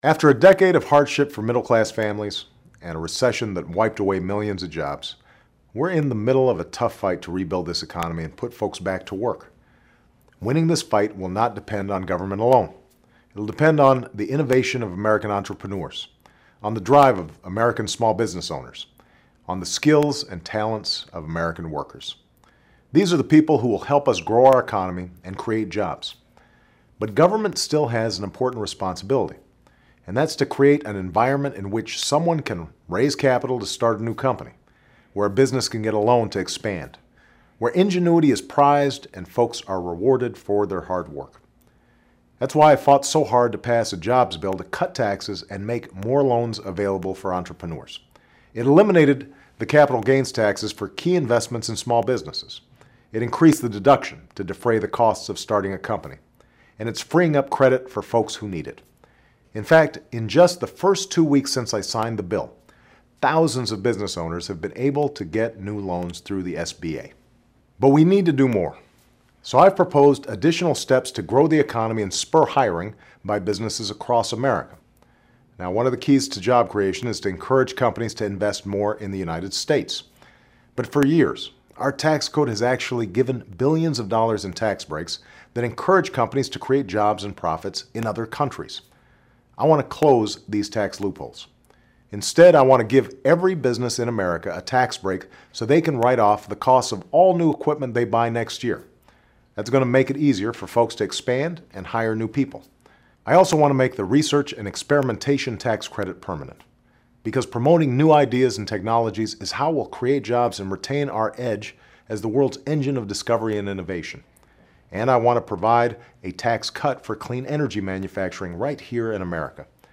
Remarks of President Barack Obama